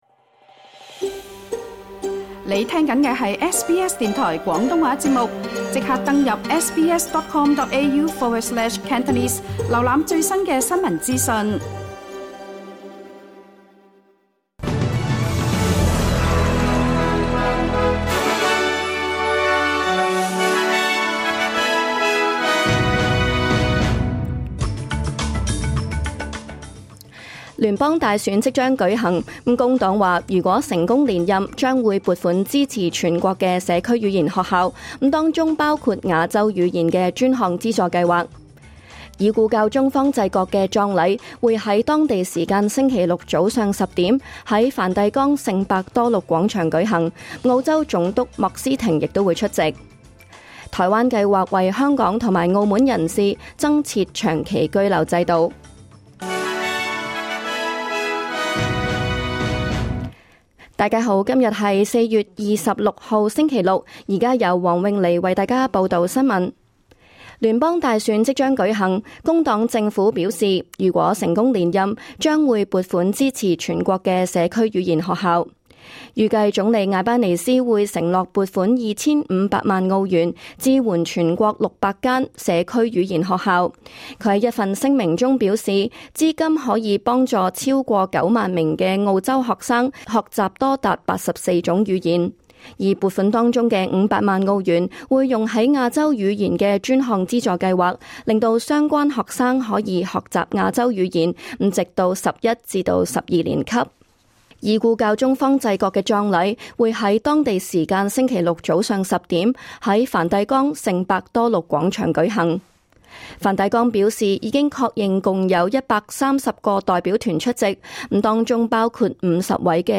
2025 年 4 月 26 日 SBS 廣東話節目詳盡早晨新聞報道。